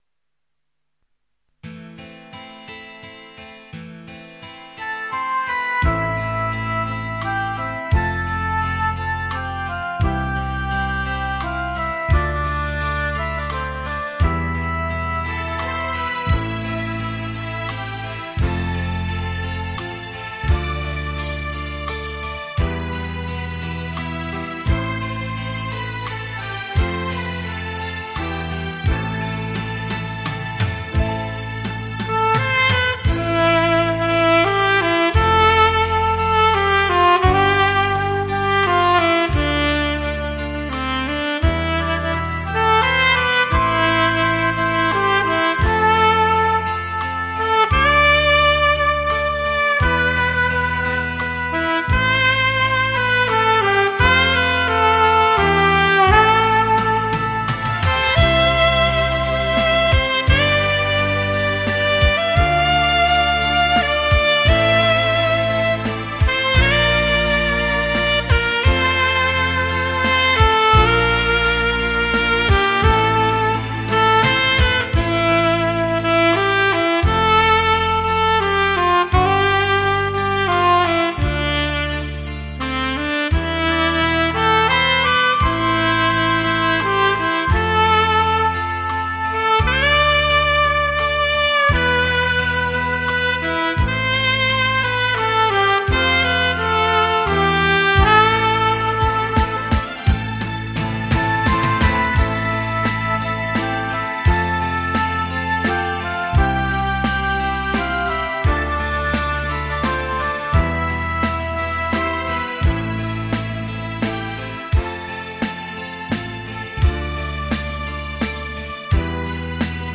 萨克斯传奇 倾情吹出荡气回肠